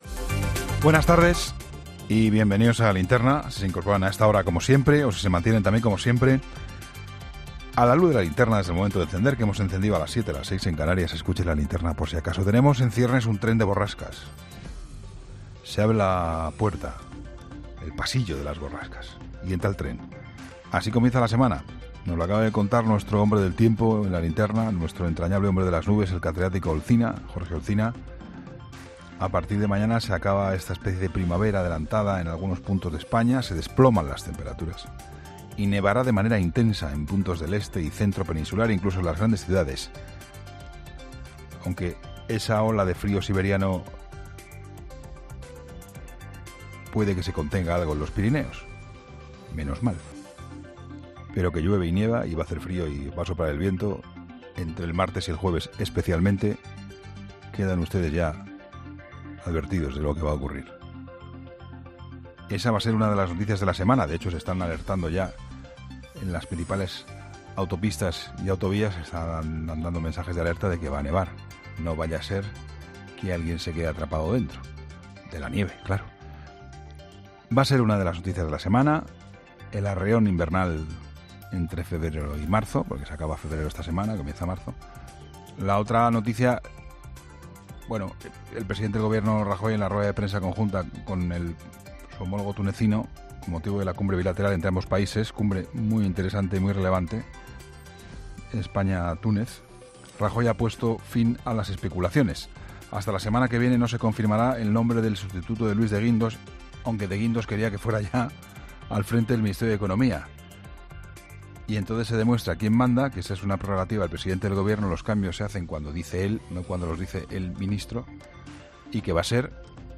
La crónica de Juan Pablo Colmenarejo